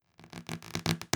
Added snap and stretch sounds for band
stretch.wav